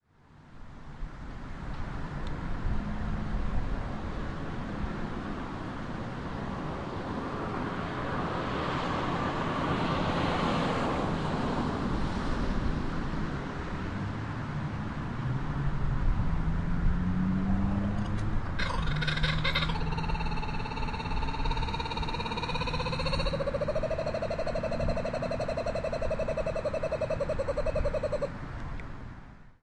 北行列车a
描述：北行的CSX货运列车在纽约州CornwallonHudson，2008年7月19日星期六约09:00。在我的后院（屏风门廊）用一对舒尔KSM27话筒录入Edirol USB前置放大器到我的笔记本电脑。
声道立体声